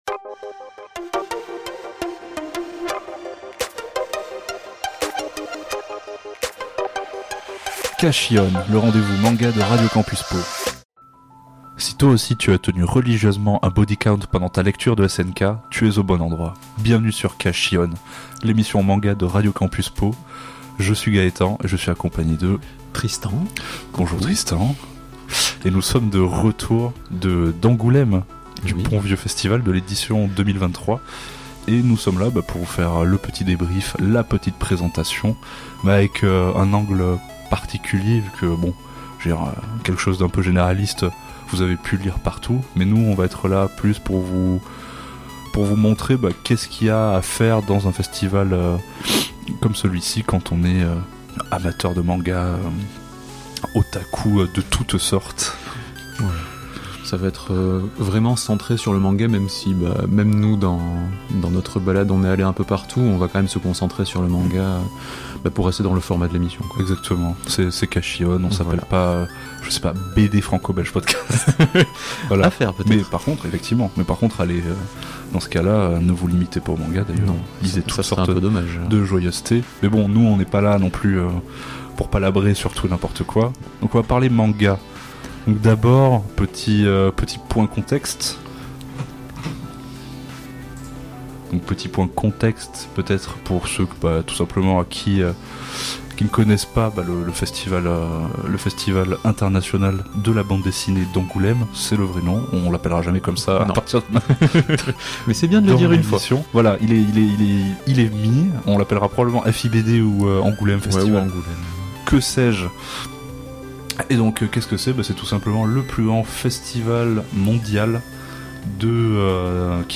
Nos deux compères reviennent du Festival de la Bande Dessinée d'Angoulême et ils ont pleins de choses à dire sur cette édition bien riche !